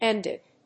アクセントénd it (áll)